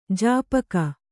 ♪ jāpaka